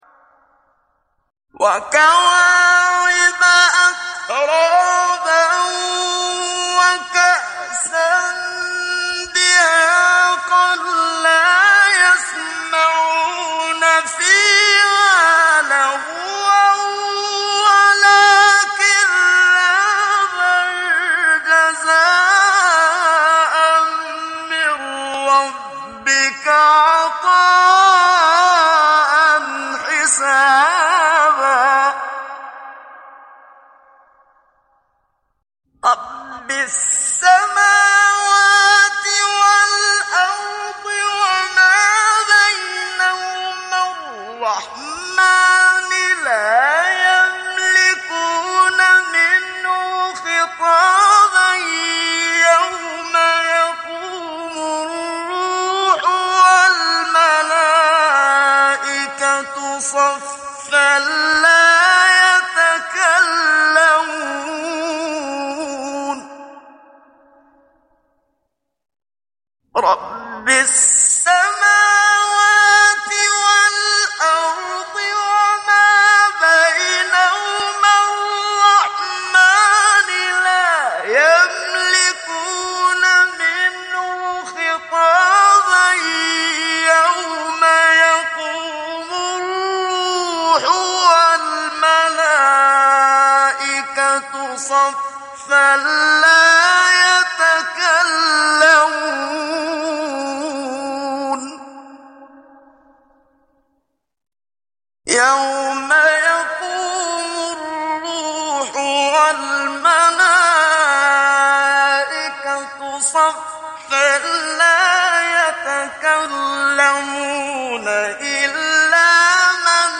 گروه شبکه اجتماعی: مقاطع صوتی از تلاوت‌های قاریان برجسته مصری را می‌شنوید.
مقطعی از محمد صدیق منشاوی در مقام نهاوند